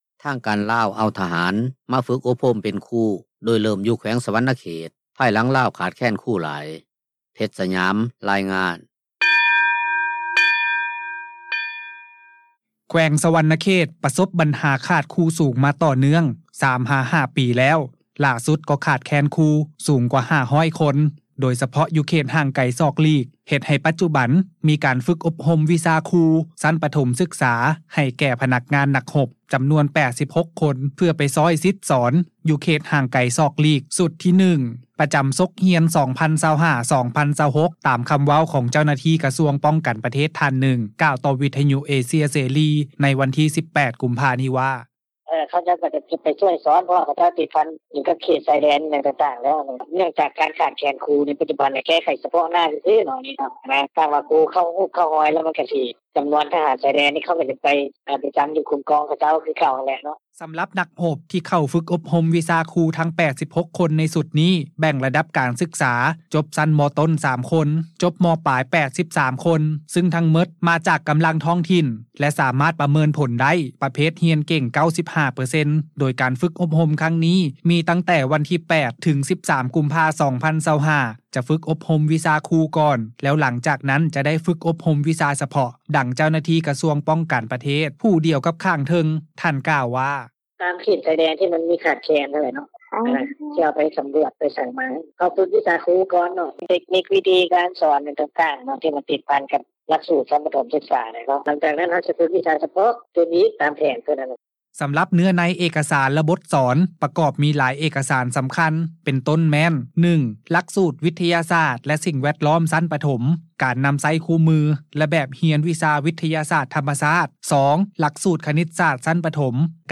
ແຂວງສະຫວັນນະເຂດ ປະສົບບັນຫາ ຂາດແຄນຄູສອນ ຕໍ່ເນື່ອງ 3 ຫາ 4 ປີ ແລ້ວ, ຫຼ້າສຸດ ກໍຂາດແຄນຄູ ສູງກວ່າ 500 ຄົນ ໂດຍສະເພາະ ຢູ່ເຂດຫ່າງໄກສອກຫຼີກ ເຮັດໃຫ້ປັດຈຸບັນ ມີການຝຶກອົບຮົມວິຊາຄູ ຊັ້ນປະຖົມສຶກສາ ໃຫ້ແກ່ພະນັກງານ-ນັກຮົບ ຈໍານວນ 86 ຄົນ ເພື່ອໄປຊ່ວຍສິດສອນ ຢູ່ເຂດຫ່າງໄກສອກຫຼີກ ຊຸດທີ 1 ປະຈໍາສົກຮຽນ 2025-2026, ຕາມຄວາມເວົ້າ ຂອງເຈົ້າໜ້າທີ່ກະຊວງປ້ອງກັນປະເທດ ທ່ານໜຶ່ງ ຕໍ່ວິທຍຸເອເຊັຽເສຣີ ໃນວັນທີ 18 ກຸມພາ ນີ້ ວ່າ: